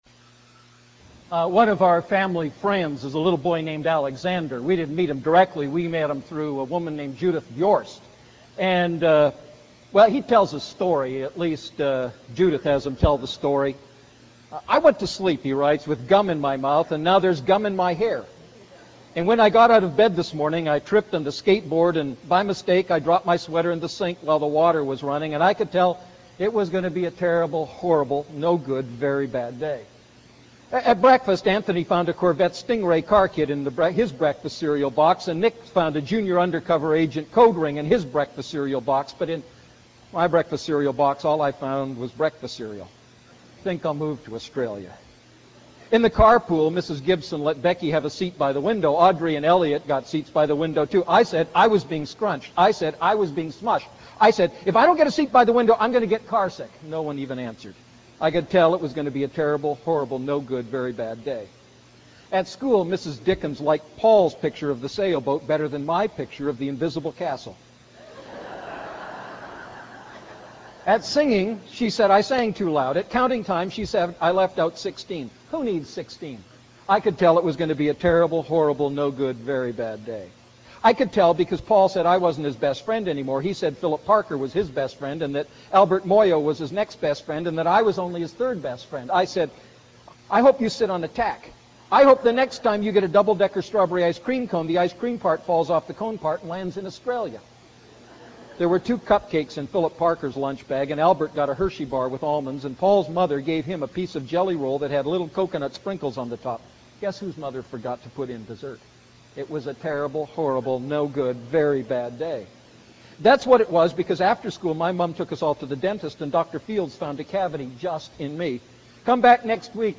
A message from the series "Luke Series I."